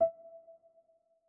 Sound / Effects / UI / Modern6.wav